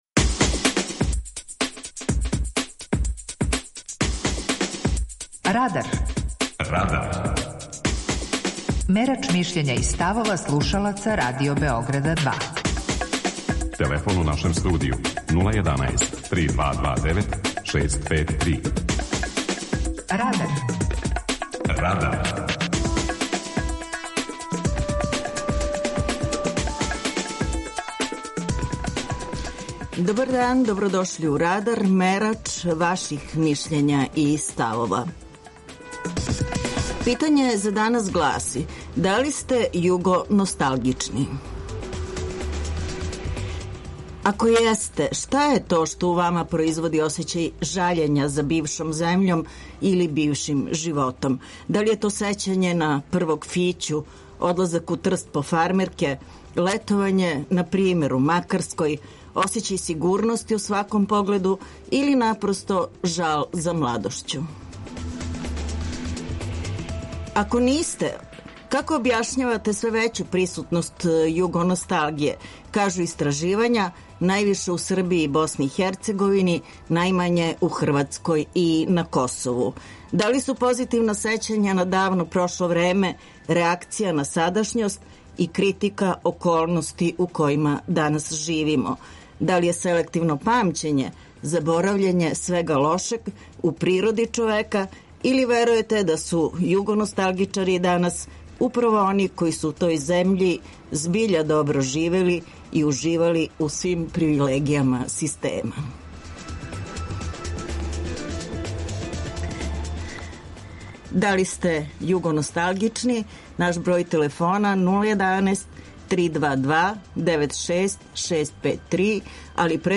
Питање за данас гласи: Да ли сте југоносталгични? преузми : 18.88 MB Радар Autor: Група аутора У емисији „Радар", гости и слушаоци разговарају о актуелним темама из друштвеног и културног живота.